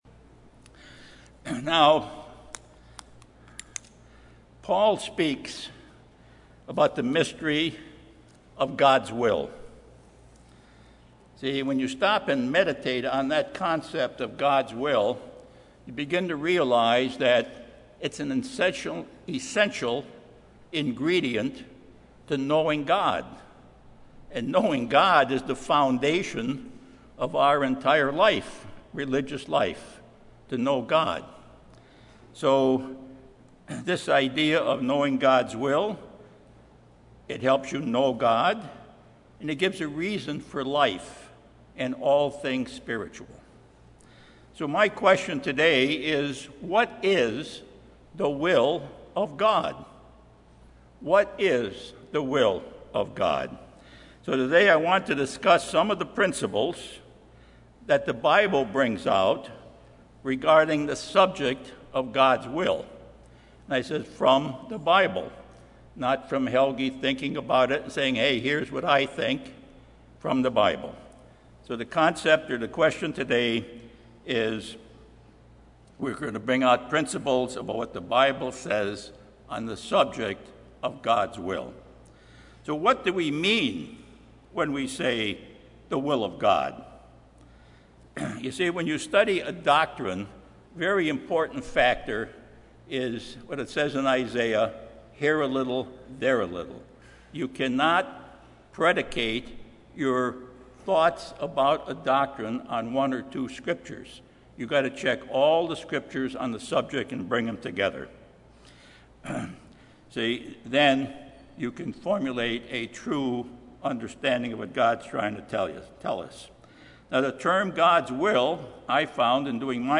Given in Los Angeles, CA